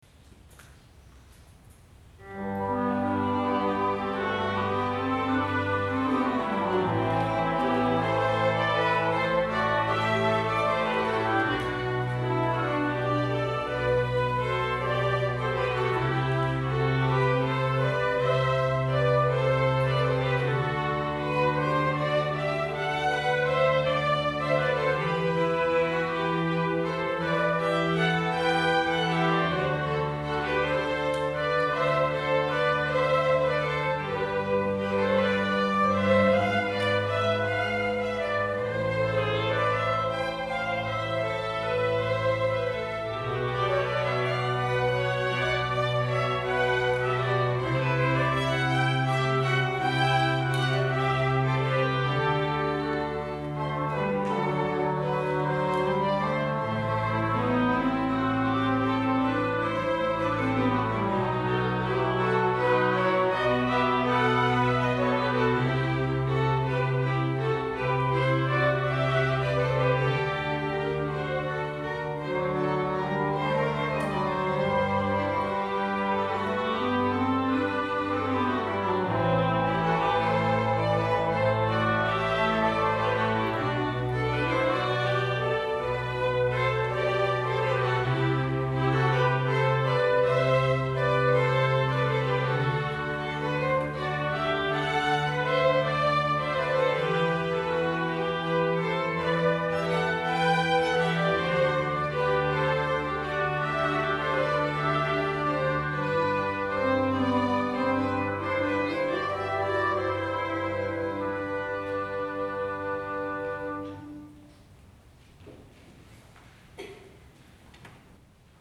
at Misakicho Church